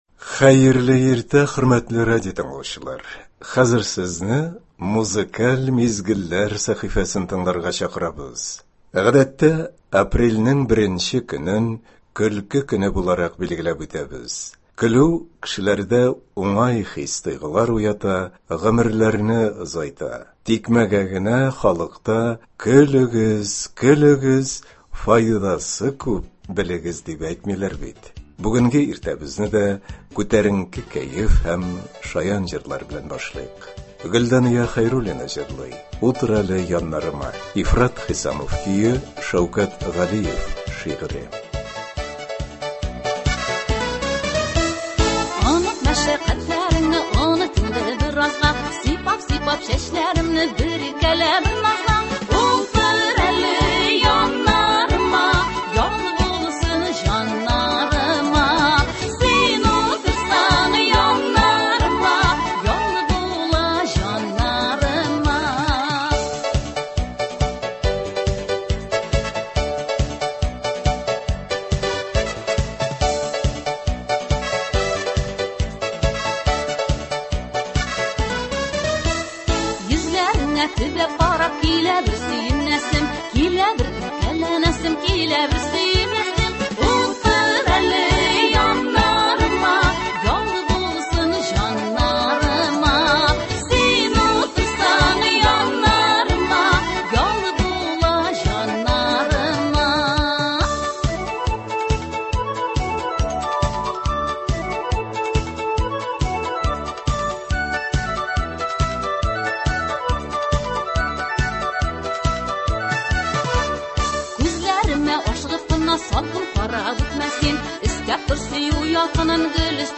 Иртәнге концерт. Шаян җырлар.